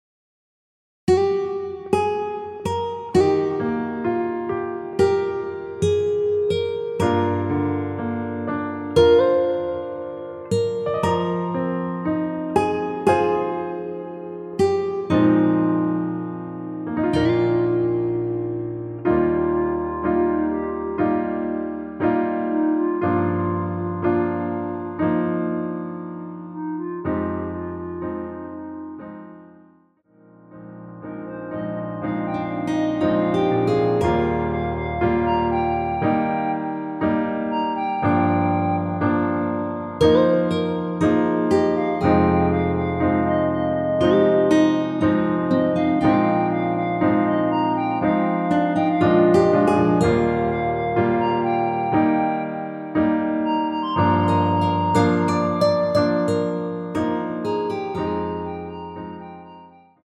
원키 멜로디 포함된 MR입니다.(미리듣기 확인)
Eb
앞부분30초, 뒷부분30초씩 편집해서 올려 드리고 있습니다.